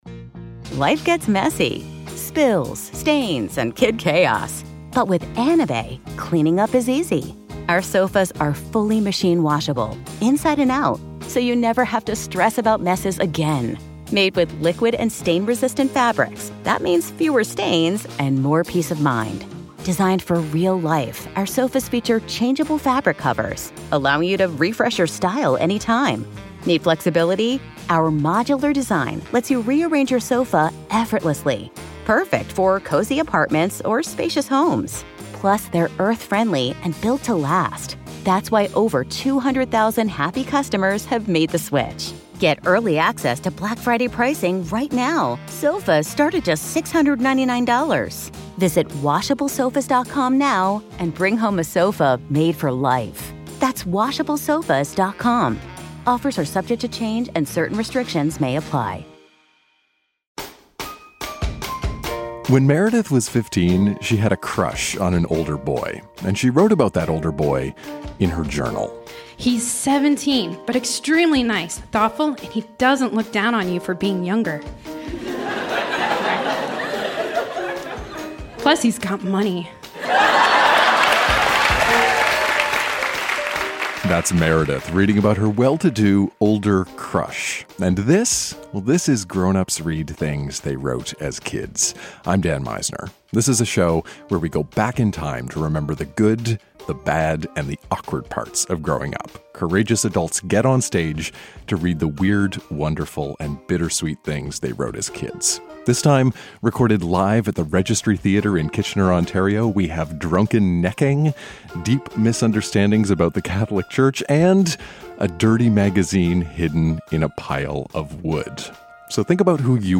Drunken necking, deep misunderstandings about the Catholic church, and a dirty magazine hidden in a pile of wood. Recorded live at The Registry Theatre in Kitchener, ON.